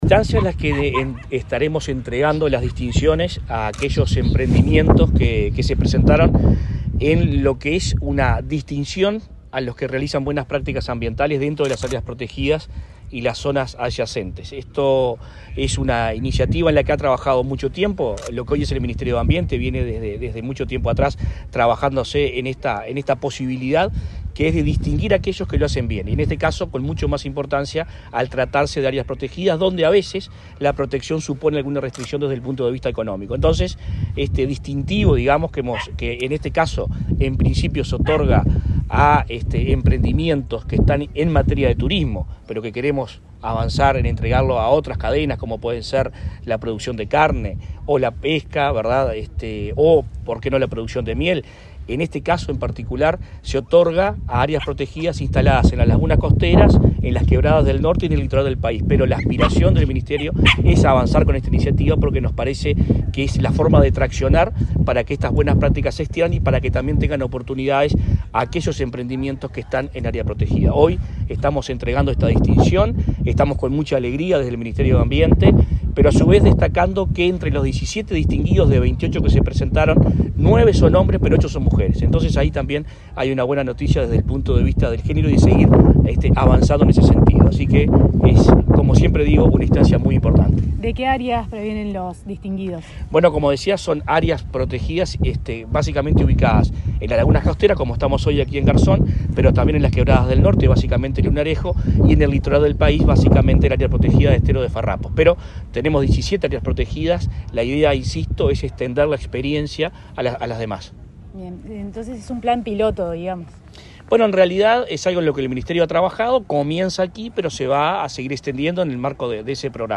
Entrevista al ministro de Ambiente, Adrián Peña